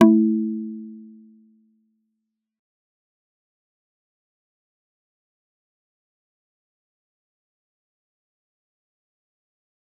G_Kalimba-A3-f.wav